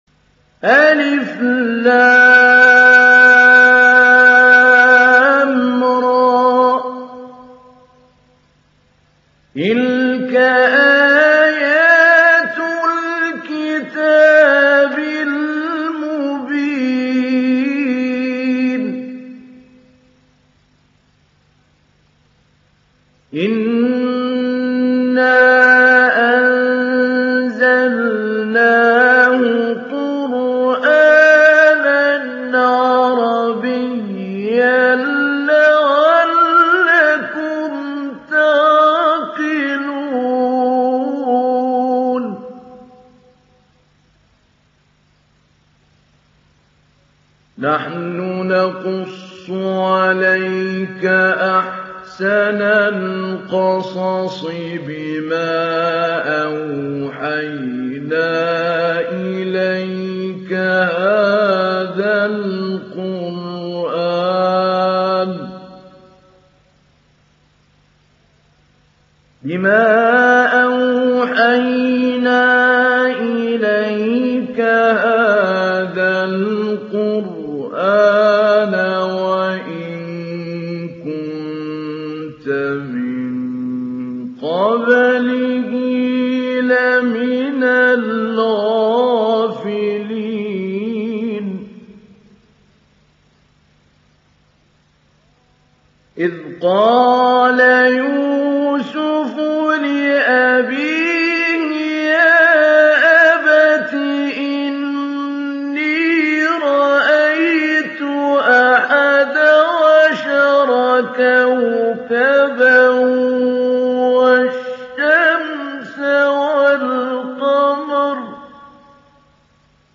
Surat Yusuf Download mp3 Mahmoud Ali Albanna Mujawwad Riwayat Hafs dari Asim, Download Quran dan mendengarkan mp3 tautan langsung penuh
Download Surat Yusuf Mahmoud Ali Albanna Mujawwad